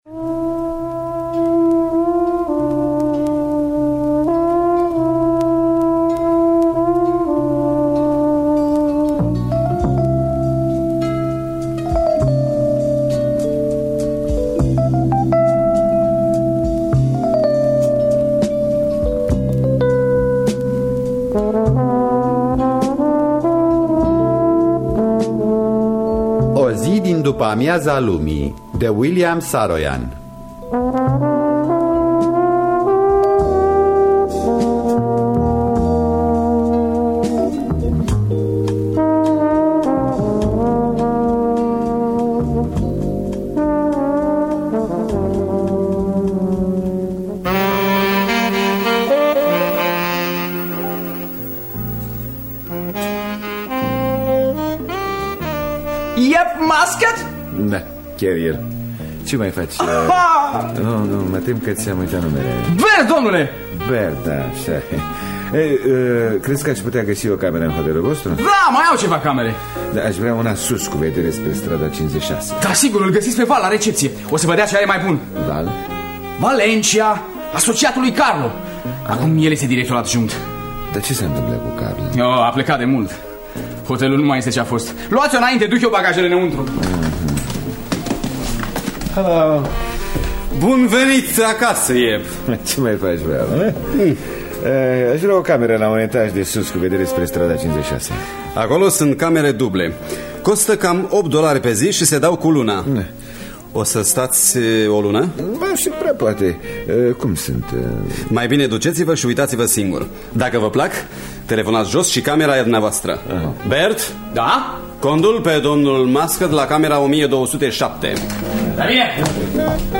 O zi din după-amiaza lumii de William Saroyan – Teatru Radiofonic Online